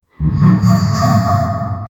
blood shaman laughing demonically, dark souls energy, path of exile, elden ring energy
blood-shaman-laughing-dem-gnygoayn.wav